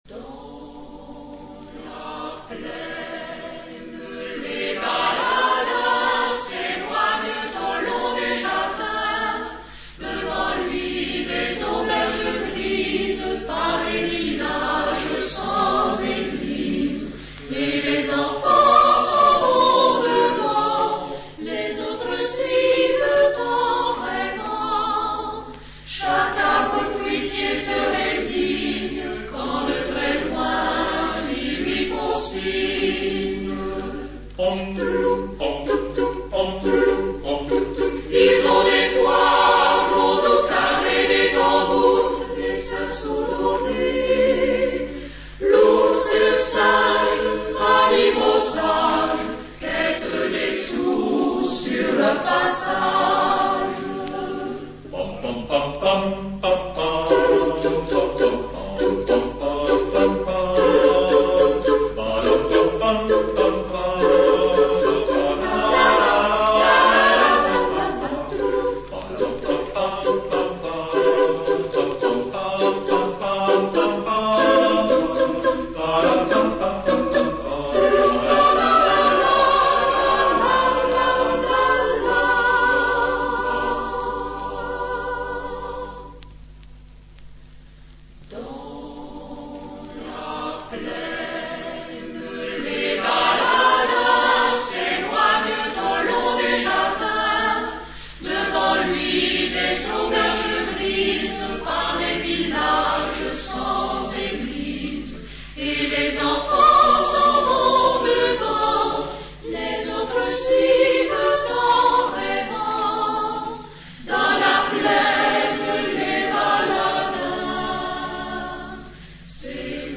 Genre-Style-Forme : contemporain ; Chanson ; Profane
Type de choeur : SATB  (4 voix mixtes )
Tonalité : sol majeur